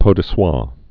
(pō də swä)